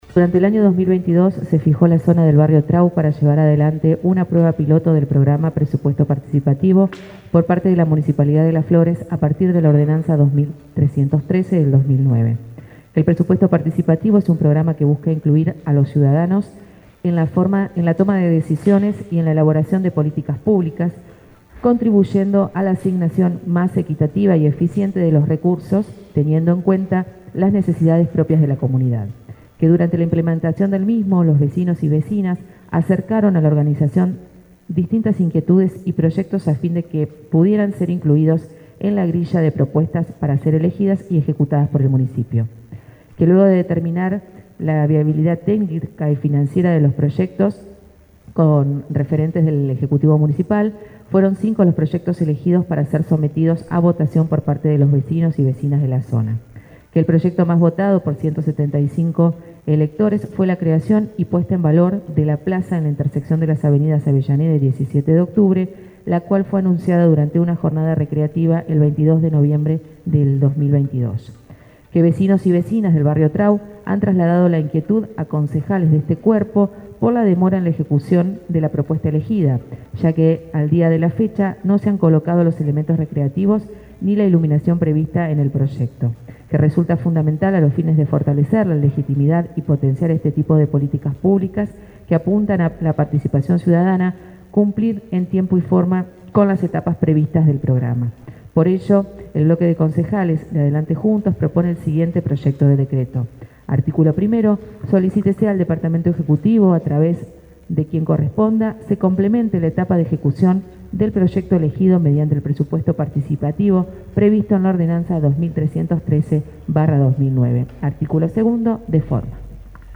Audio debate Presupuesto Partipativo: